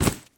sfx
land.wav